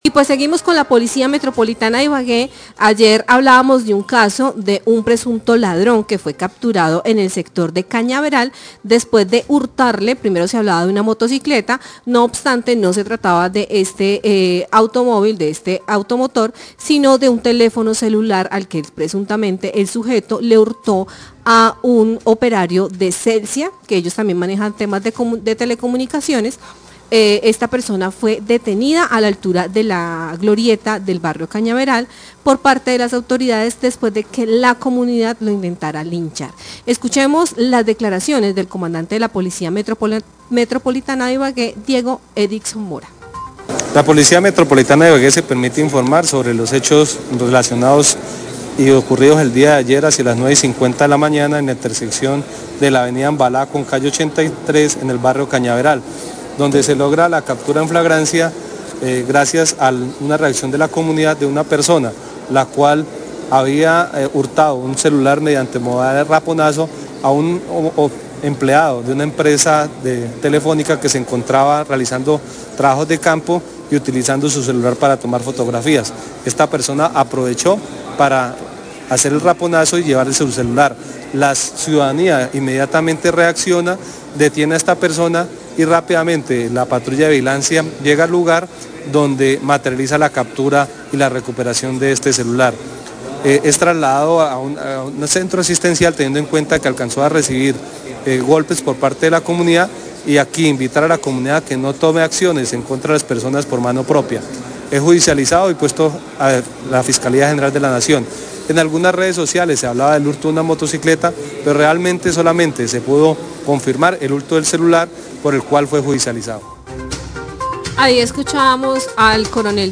Radio
El comandante de la policía de Ibagué Diego Édison Mora informa que el día 28 de mayo de 2024 a la 9:50 am se presentó el hurto de un celular a operario de empresa de telecomunicaciones con la modalidad de raponazo y aclara que no se trato de un vehículo automotor (motocicleta) como se ha informado en otros medios de comunicación.